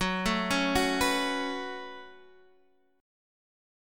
F#madd11 Chord
Listen to F#madd11 strummed